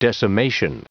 Prononciation du mot decimation en anglais (fichier audio)
Prononciation du mot : decimation